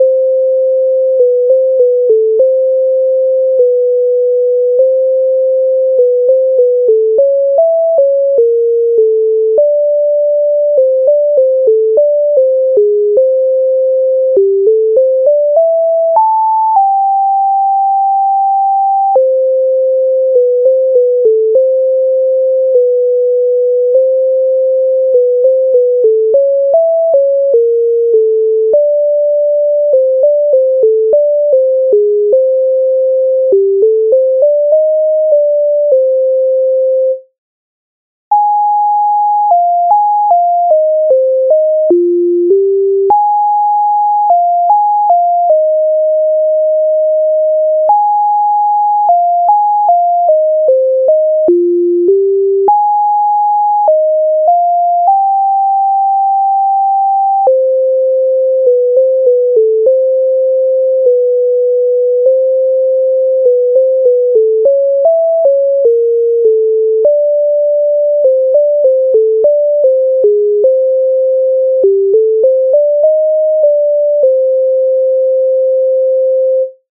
MIDI файл завантажено в тональності C-dur